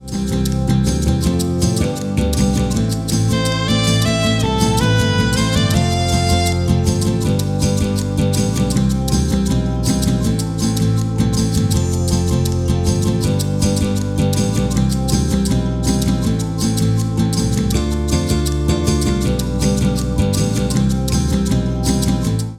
Podkład do autorskiej piosenki pt.